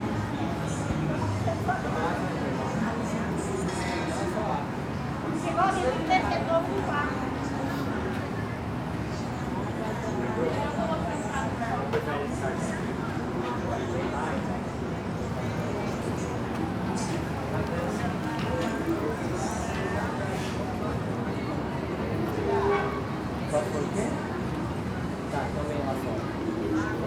CSC-04-254-LE - Ambiencia rua comercio loja com caixa de som, pessoas, passos, transito longe.wav